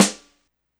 Snr Dryrik.wav